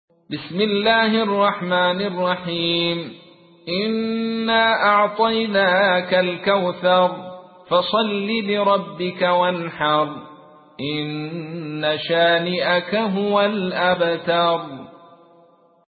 تحميل : 108. سورة الكوثر / القارئ عبد الرشيد صوفي / القرآن الكريم / موقع يا حسين